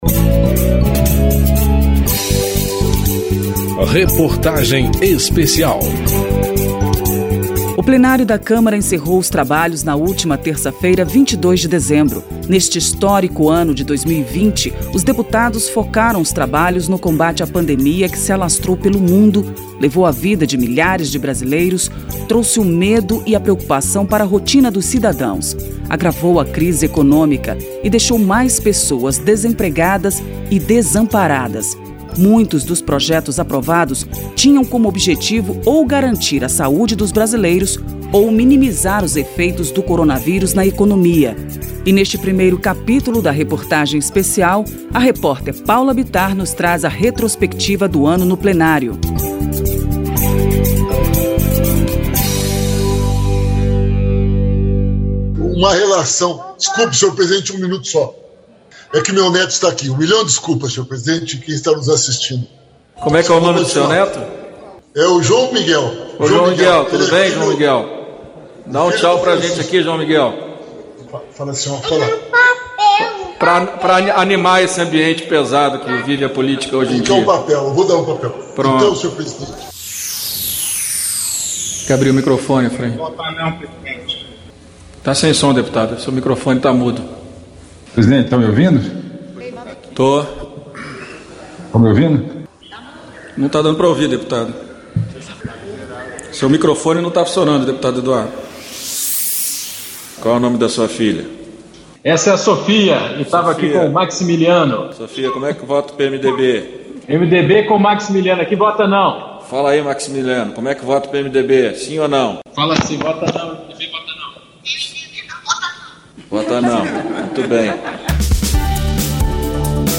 202012__ REPORTAGEM ESPECIAL - RETROSPECTIVA 2020 - CAP. 01